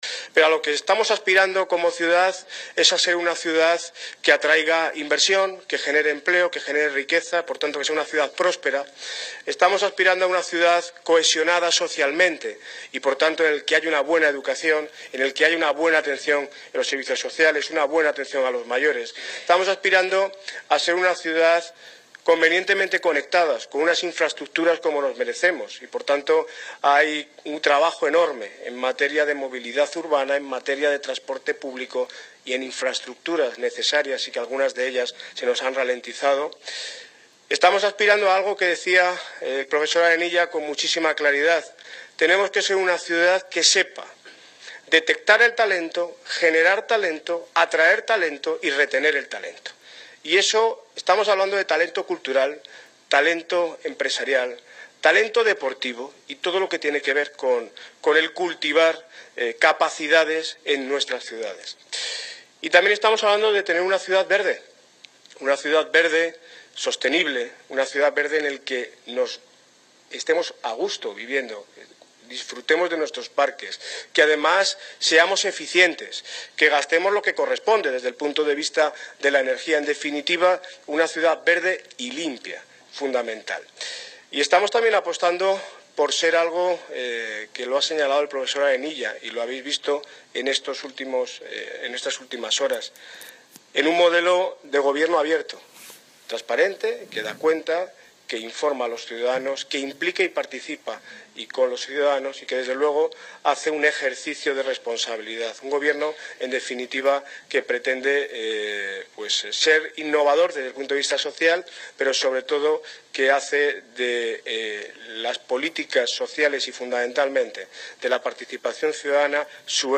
Audio - Daniel Ortiz (Alcalde de Móstoles) Sobre Foros Ciudadanos